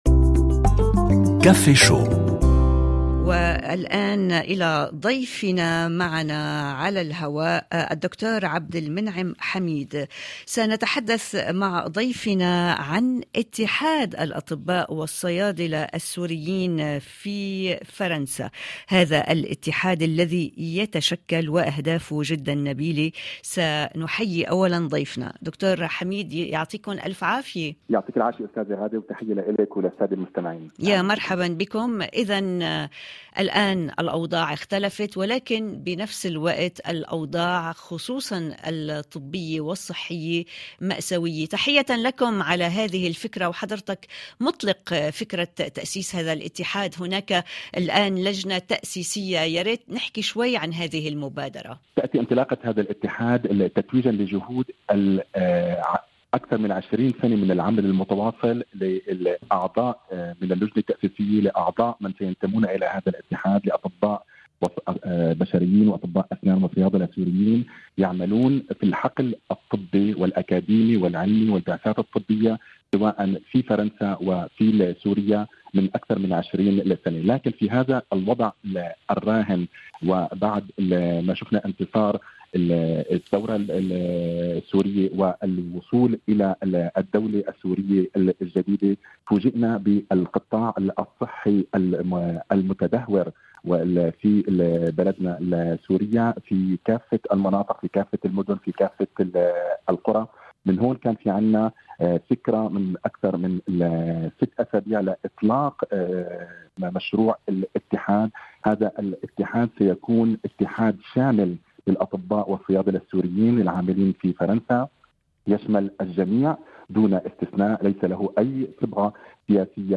مجلة صباحية يومية يلتقي فيها فريق كافيه شو مع المستمعين للتفاعل من خلال لقاءات وفقرات ومواضيع يومية من مجالات مختلفة : ثقافة، فنون، صحة، مجتمع، بالاضافة إلى الشأن الشبابي عبر مختلف بلدان العالم العربي.